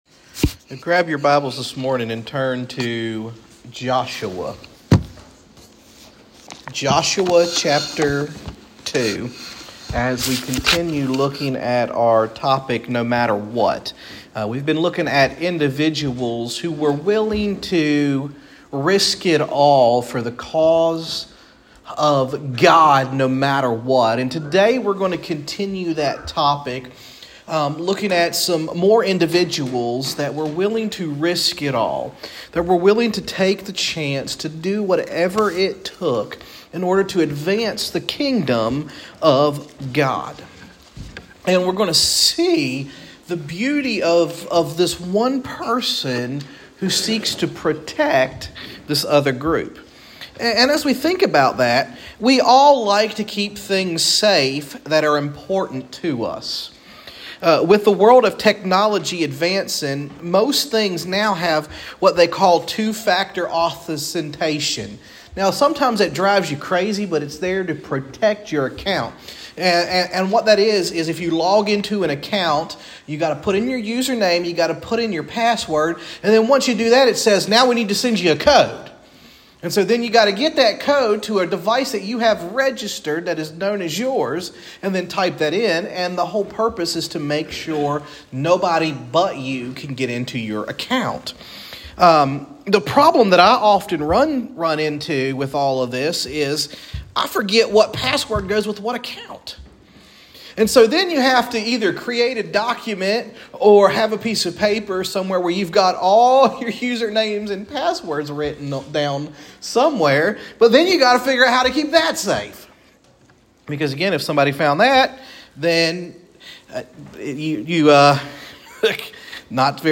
Sermons | Hopewell First Baptist Church